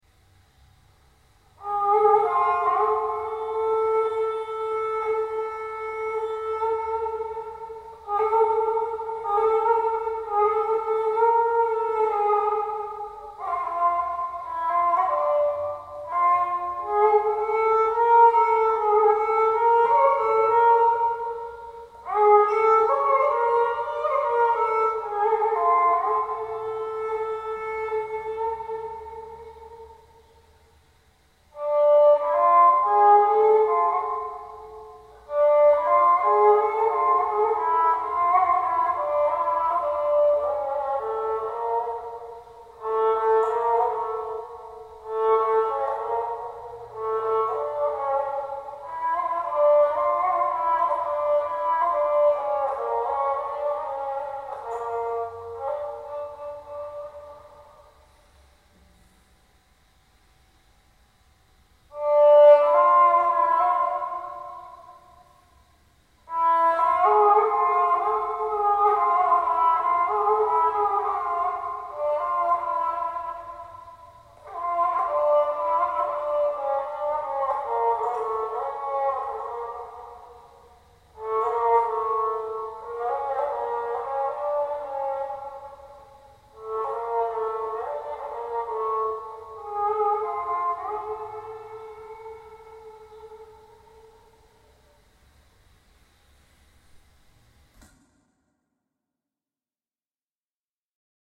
Kemençe
Türk musikisinin bu en küçük sazı, boy-bosundan umulmayacak güçte bir ses yüksekliğine ve tınısına sahiptir (sesler, diğer telli sazlarda olduğu gibi teli kısmen sağırlaştıran parmak ucu etinden değil, tırnağın sert boynuzsu yapıdaki yüzeyinden elde edildiği için).